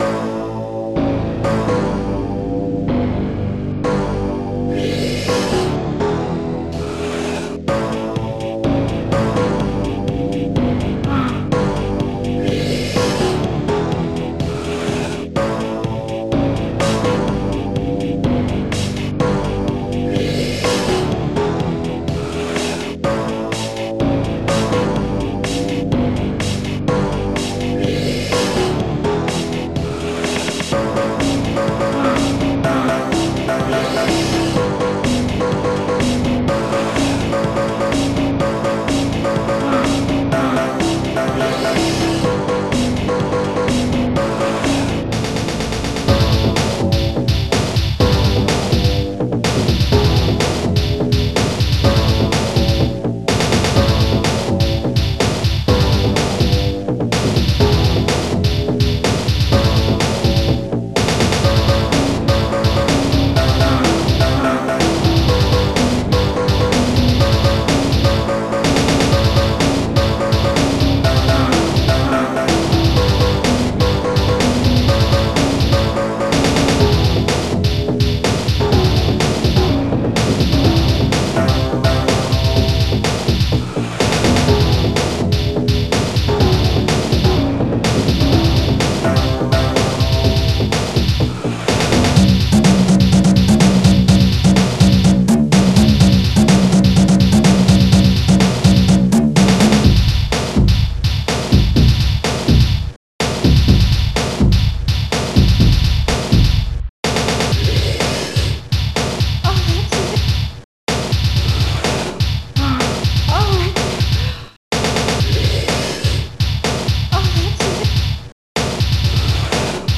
Protracker Module  |  1996-03-11  |  181KB  |  2 channels  |  44,100 sample rate  |  3 minutes, 42 seconds
Protracker and family